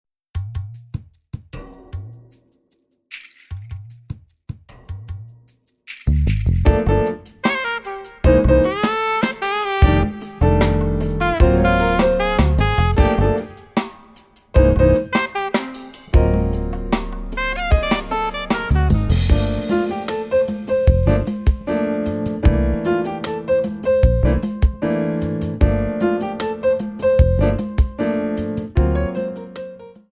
Jazz / Modern
4 bar intro
allegretto